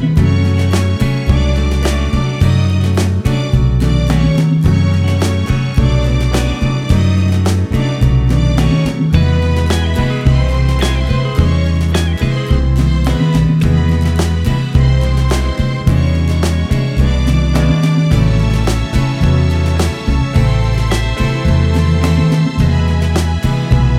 One Semitone Up Pop (1960s) 2:28 Buy £1.50